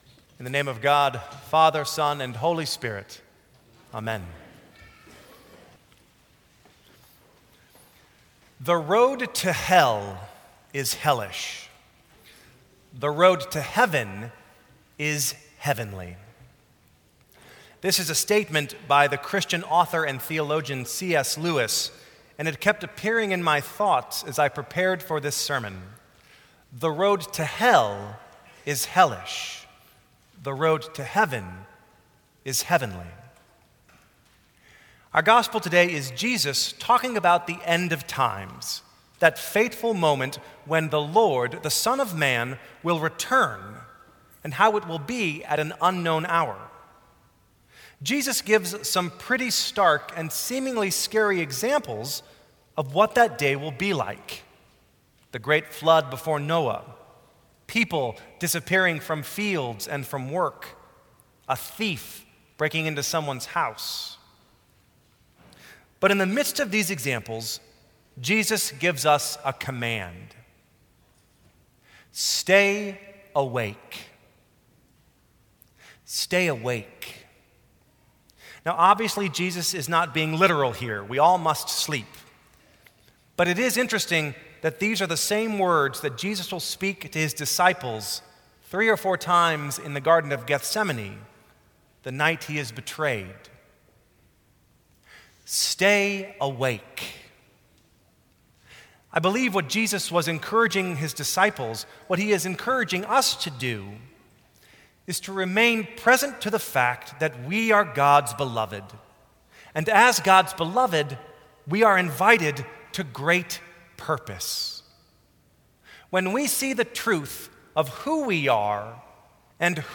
Sermons from St. Cross Episcopal Church 12/1/2013 Dec 17 2013 | 00:10:09 1x 00:00 / 00:10:09 Subscribe Share Apple Podcasts Spotify Overcast RSS Feed Share Link Embed